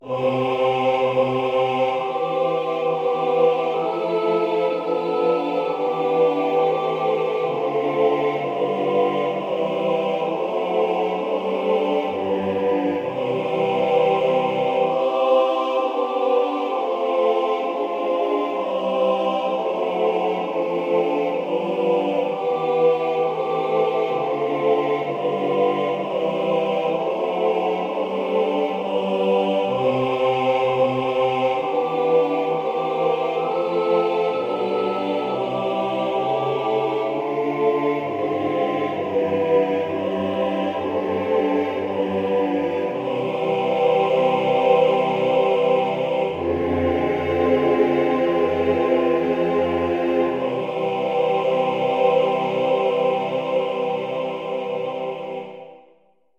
Sånger till prästinstallation 3/2 2013
Gud som haver Barnen kär, dator